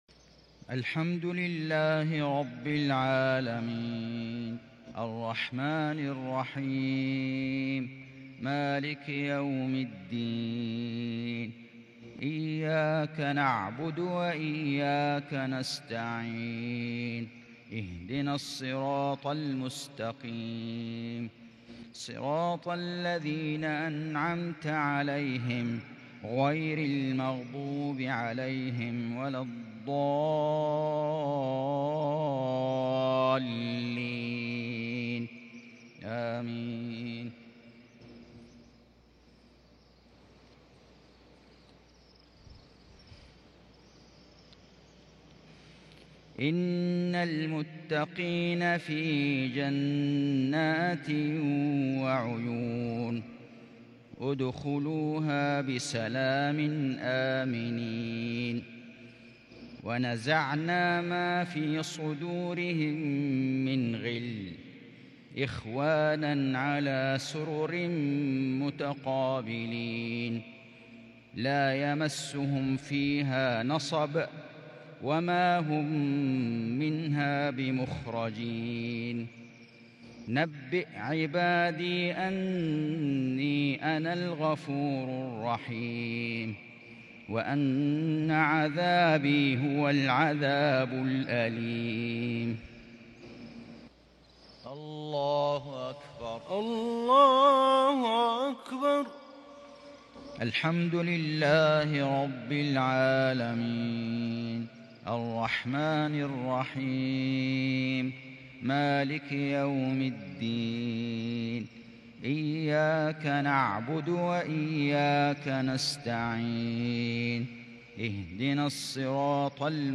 مغرب الخميس 1 شوال 1442هــ من سورتي الحجر و القمر | Maghrib prayer from Surat Al-Hijr and Al-Qamar 13/5/2021 > 1442 🕋 > الفروض - تلاوات الحرمين